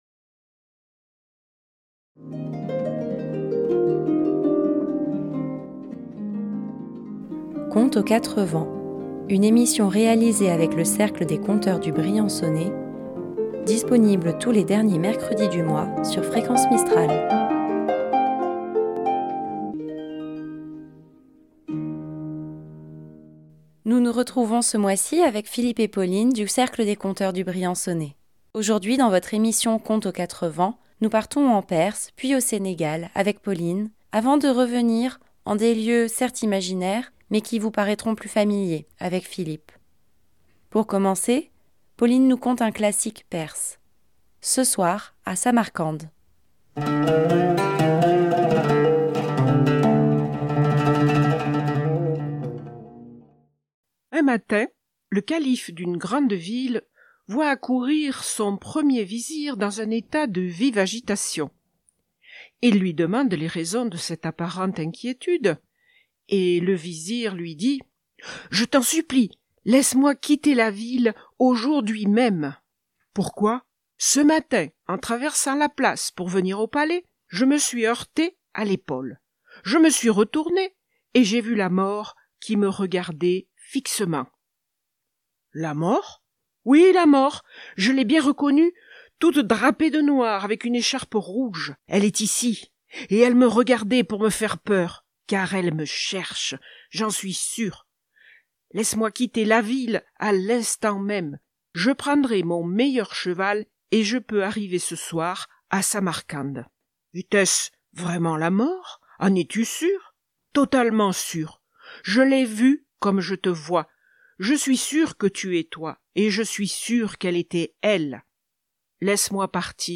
Tous les derniers mercredi du mois, à 16h, retrouvez le Cercle des conteurs du Briançonnais pour une balade rêveuse.